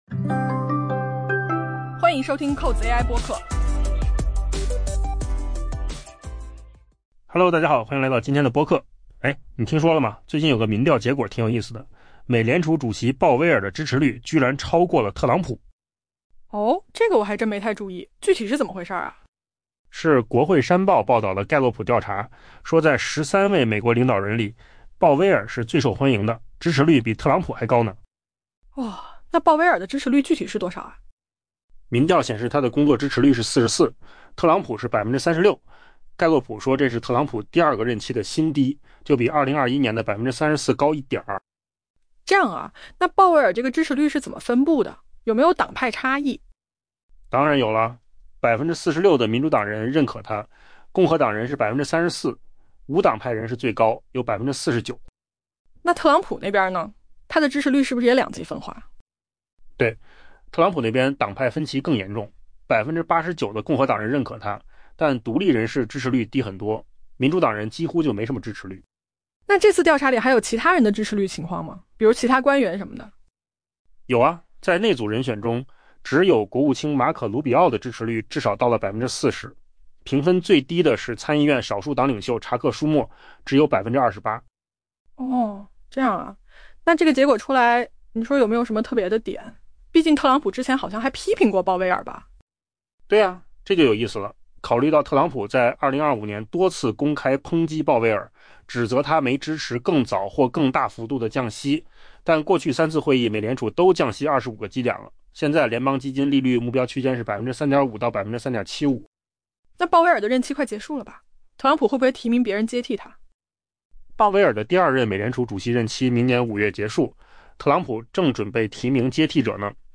AI播客：换个方式听新闻